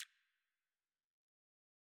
TMPerc_1.wav